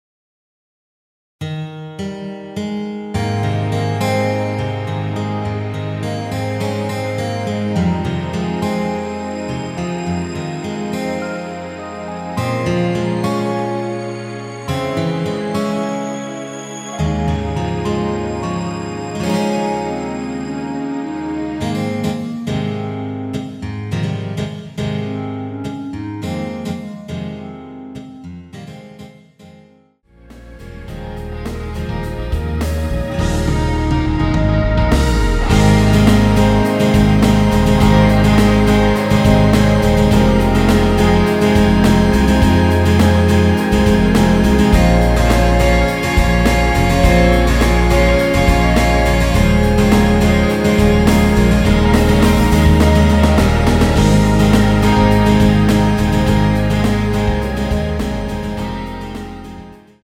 원키에서(-3)내린 멜로디 포함된 MR입니다.
Ab
앞부분30초, 뒷부분30초씩 편집해서 올려 드리고 있습니다.
중간에 음이 끈어지고 다시 나오는 이유는